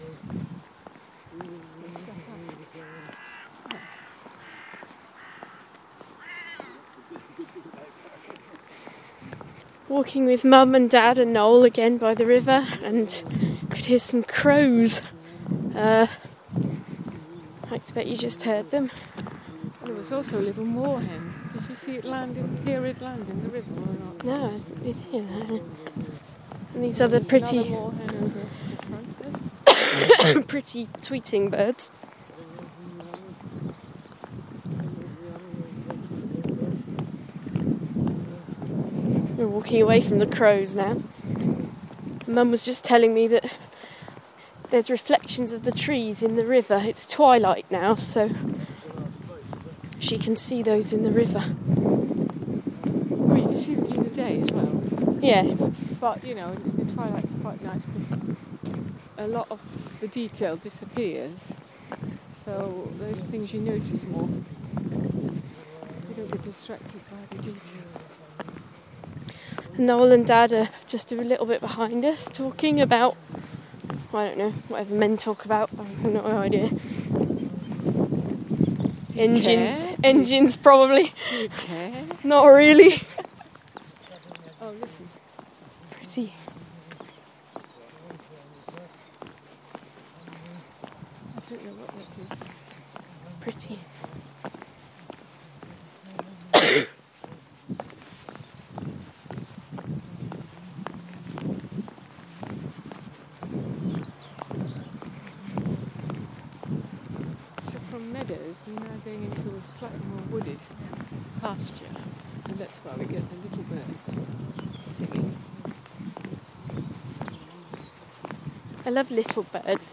Twilight Walk Boo part 1. Walking by the River Ooze in Stony Stratford, listening to crows and pretty birdsong